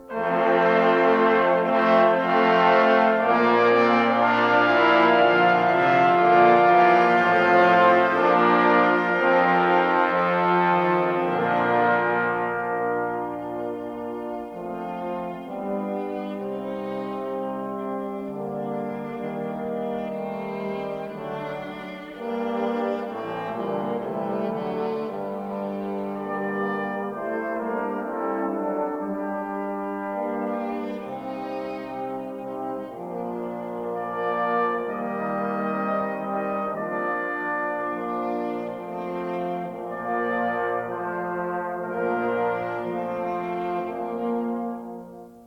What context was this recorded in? A 1960 stereo recording